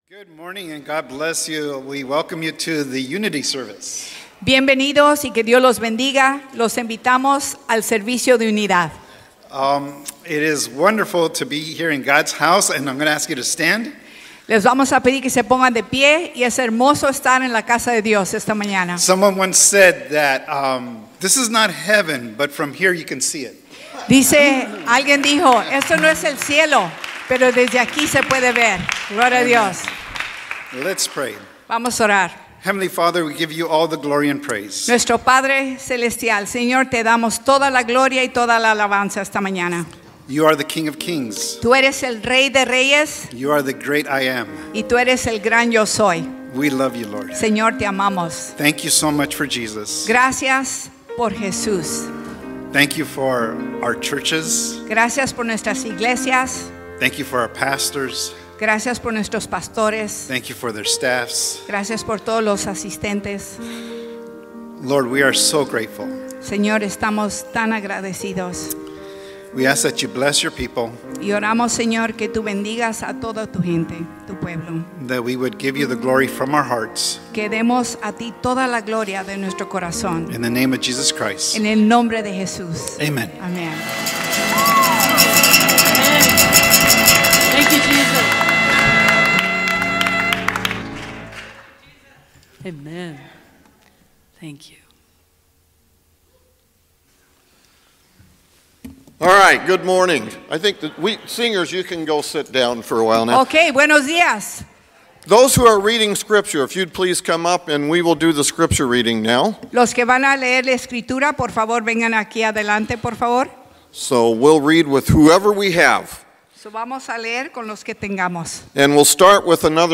Unity Service 11-24-24
All the Churches meeting on the Magnolia Baptist Campus worshiping together.